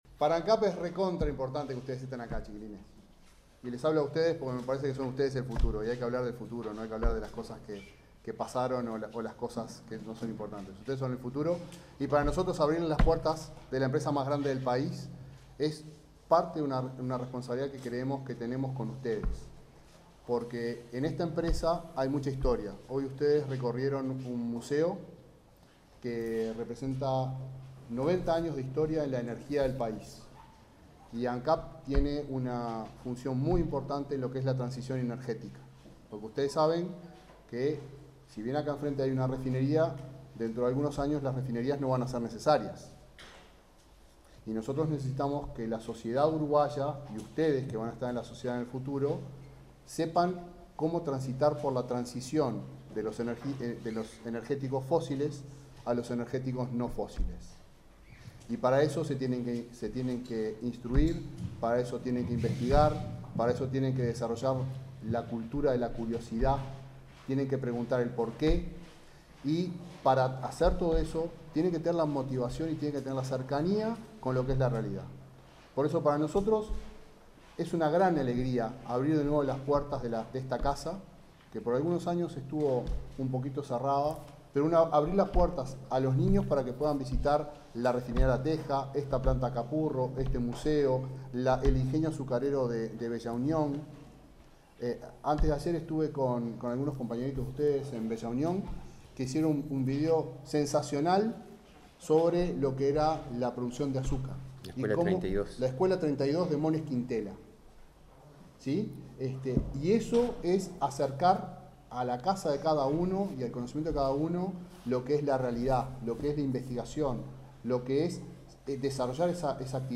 Palabras de autoridades de Ancap y la ANEP
El presidente de Ancap, Alejandro Stipanicic, y el presidente de la ANEP, Robert Silva, firman este viernes 10 en el Polo Industrial de Capurro un